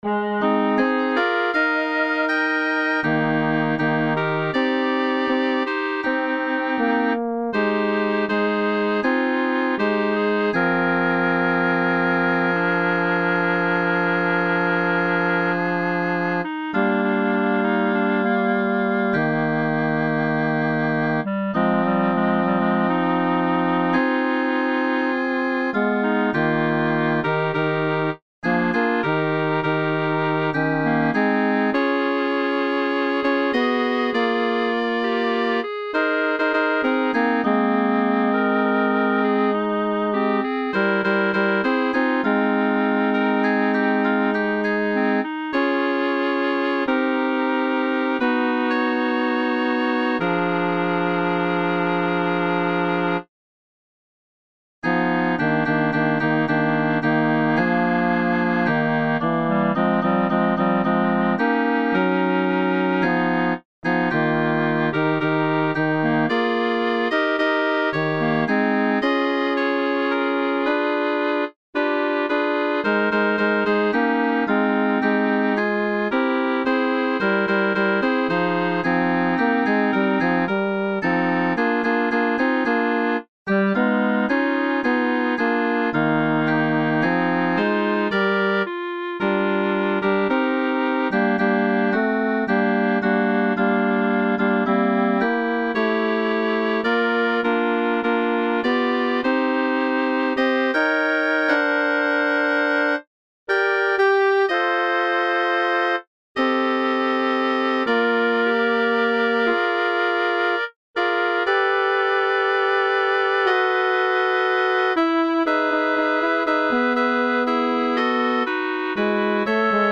Midi Preview